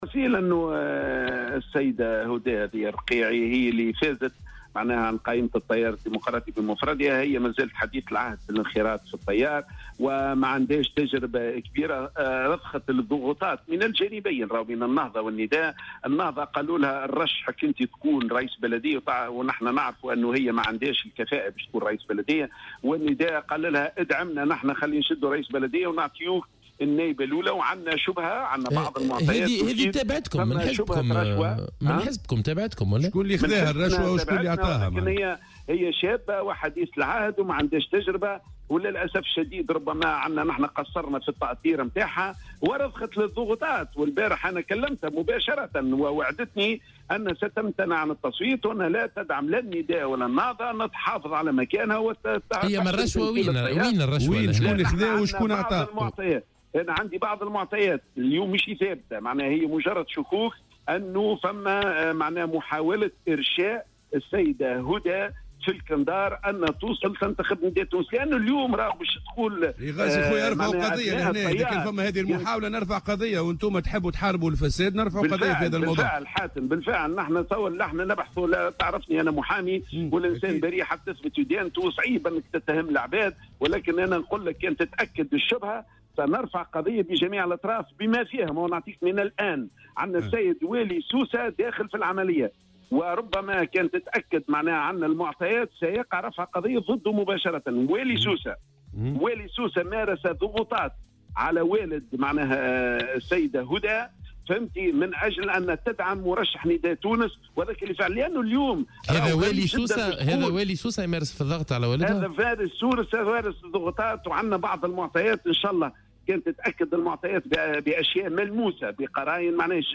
وأضاف في مداخلة له اليوم في برنامج "صباح الورد" على "الجوهرة أف أم"